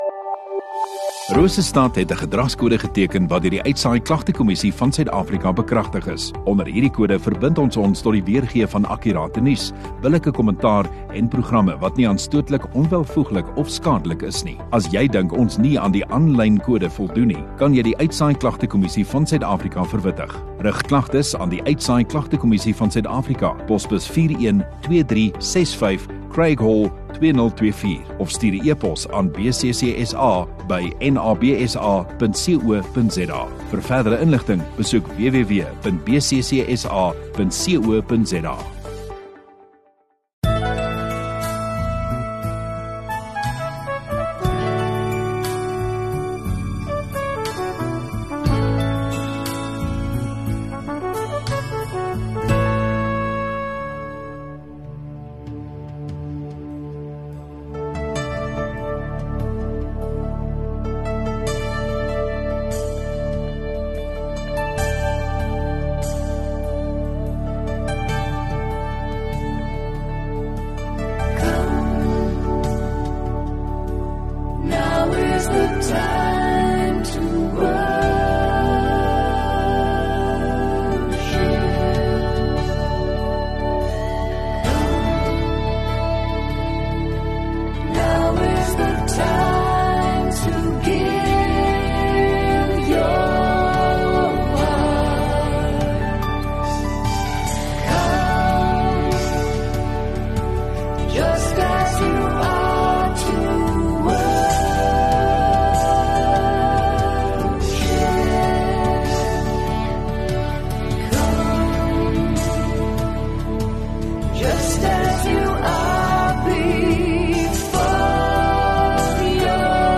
View Promo Continue Radio Rosestad Install Rosestad Godsdiens 5 Apr Sondagaand Erediens